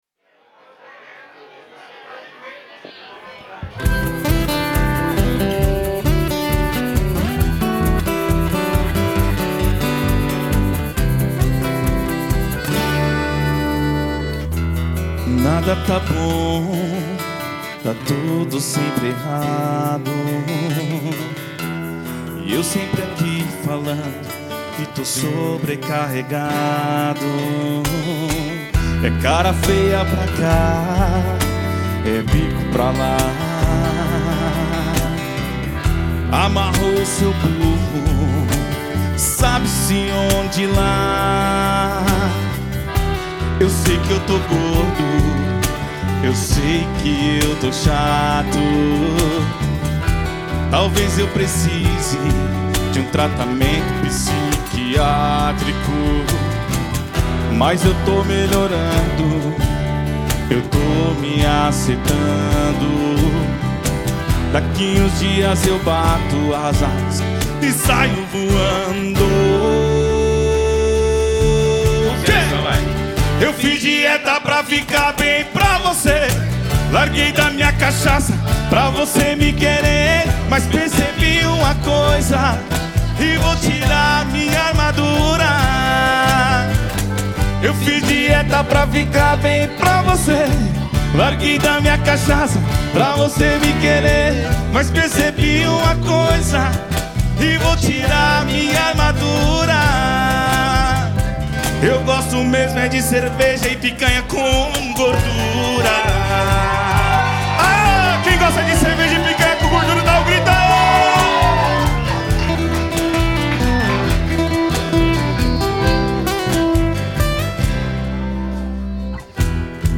EstiloSertanejo